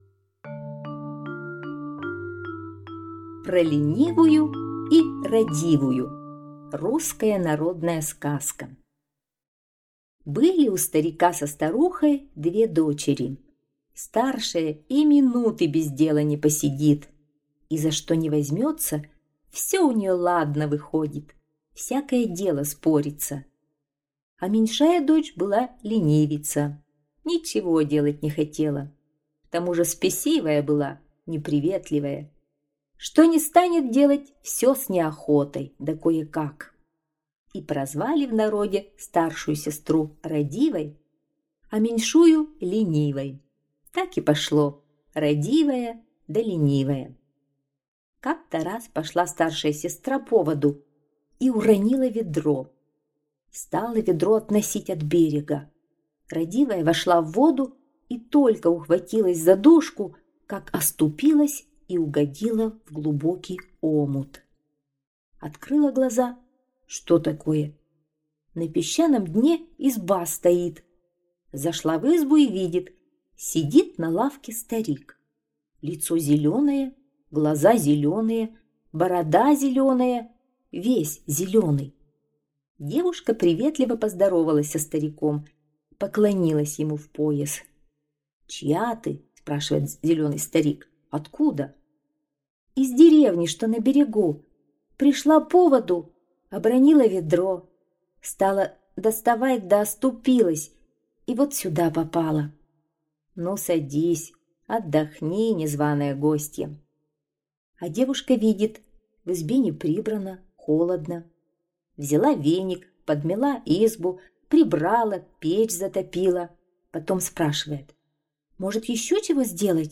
Про Ленивую и Радивую - русская народная аудиосказка - слушать онлайн